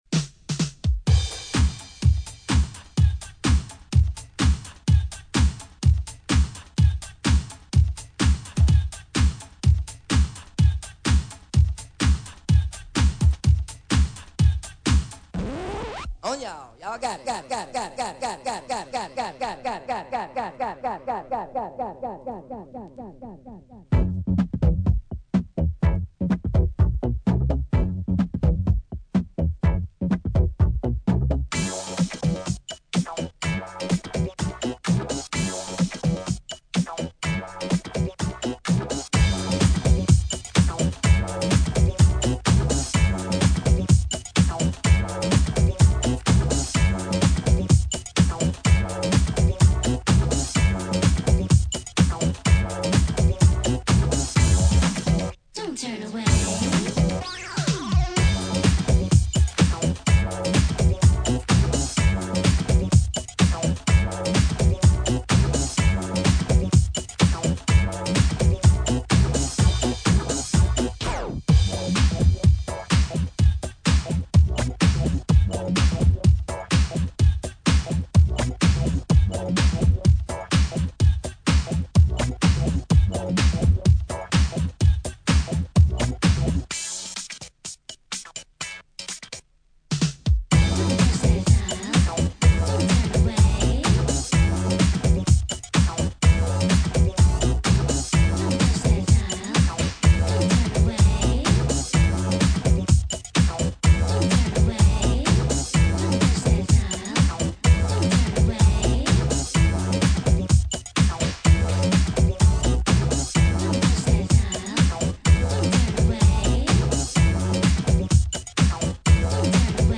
Media: Vinyl Only